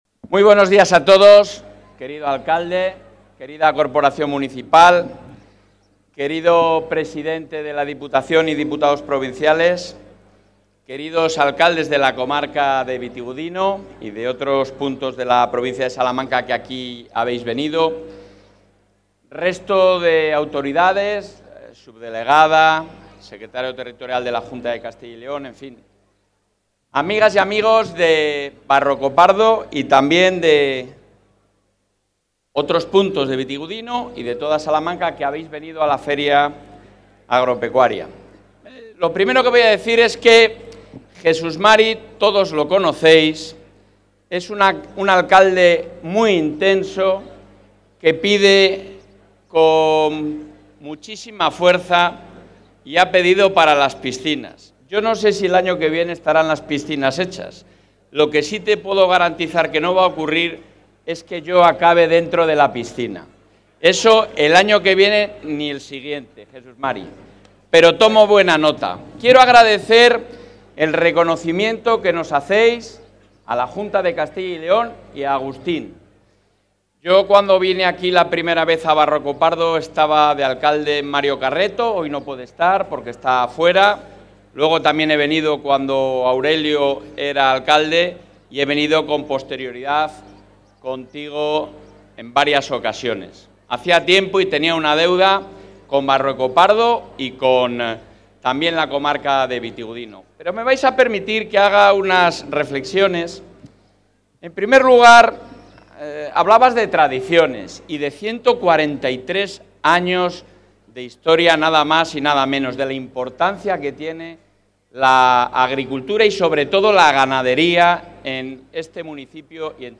El presidente de la Junta de Castilla y León, Alfonso Fernández Mañueco, ha participado hoy en el acto central de la Feria de...
Intervención del presidente de la Junta.